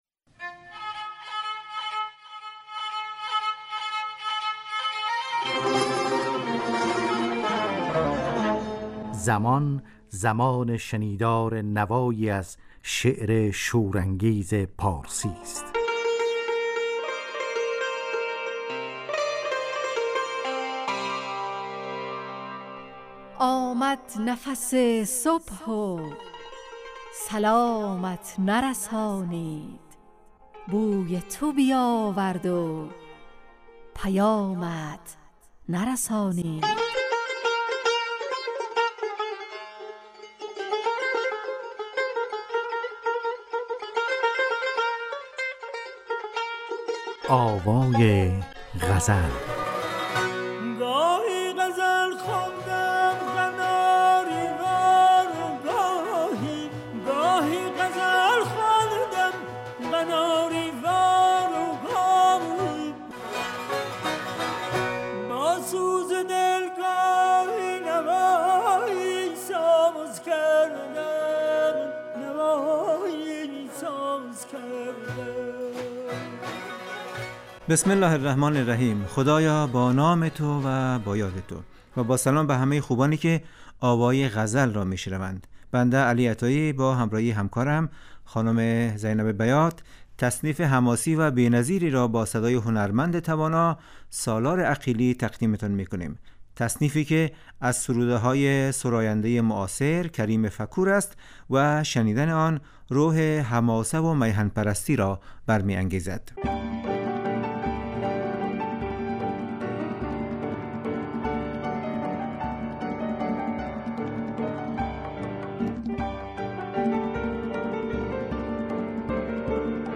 آوای غزل نواهنگ رادیویی در جهت پاسداشت زبان و ادبیات فارسی . خوانش یک غزل فاخر از شاعران پارسی گوی و پخش تصنیف زیبا از خوانندگان نامی پارسی زبان .